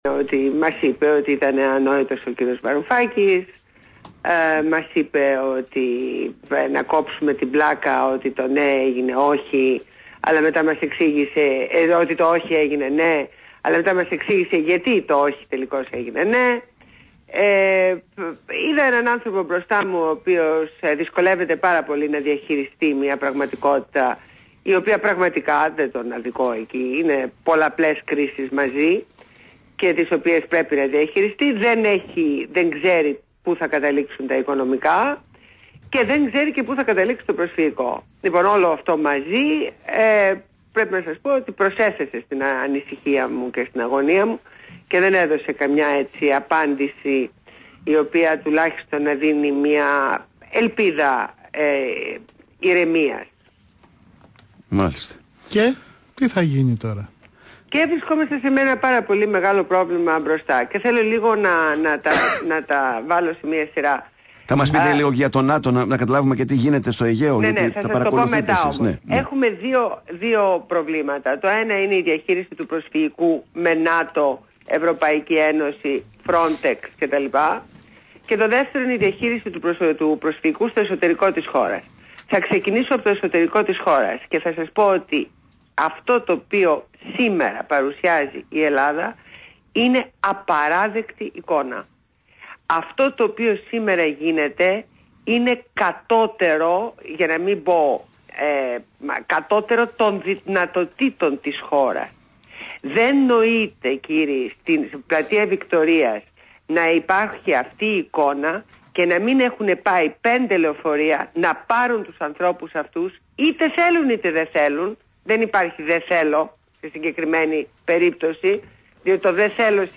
Συνέντευξη στο ραδιόφωνο BHMAfm